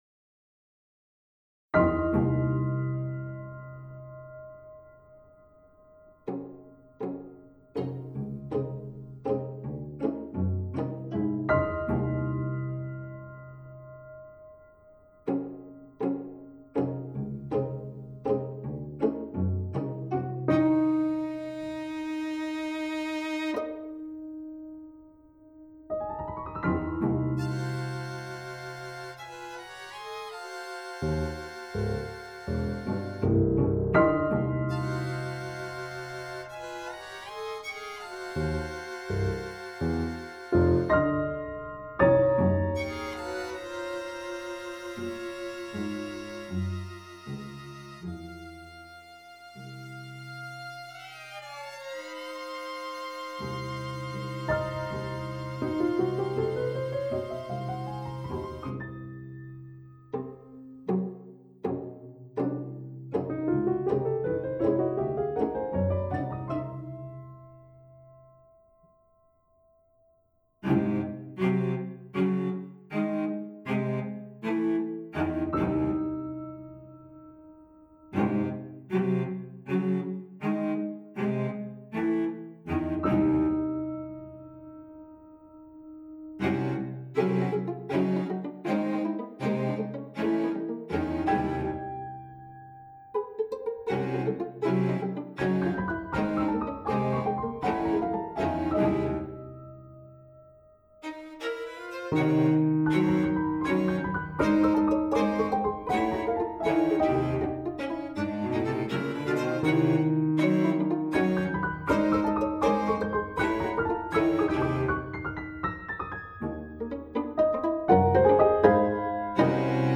Instr: Piano Septet